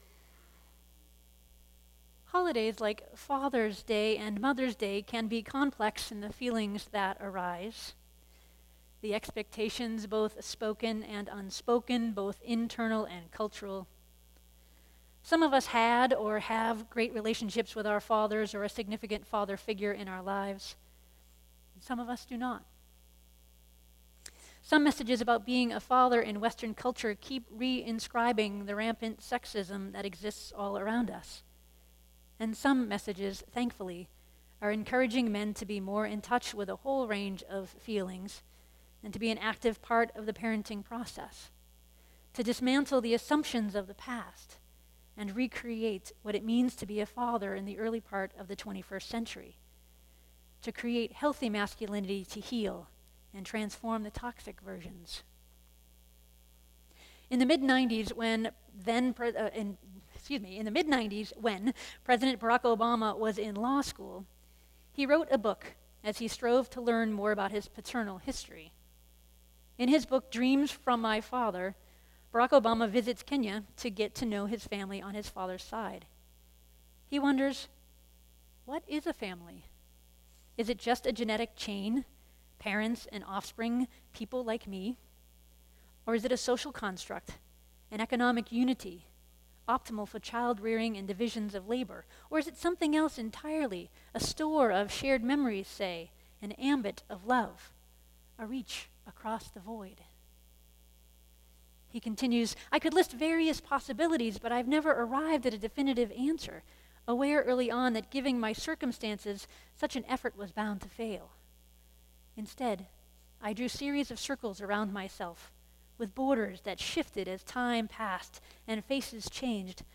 We’ll explore fathering, be blessed by the choirs, and celebrate community with a picnic after the service.